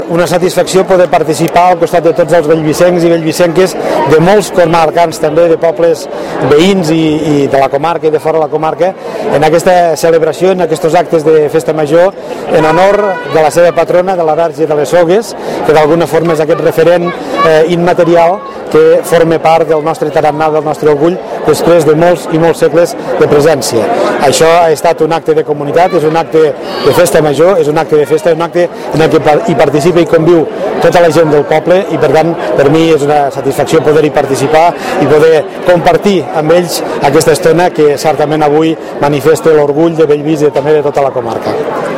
Posteriorment, a la plaça Verge de les Sogues, el president de la Diputació de Lleida ha adreçat unes paraules als veïns de Bellvís i altres municipis de la comarca que han assistit als actes.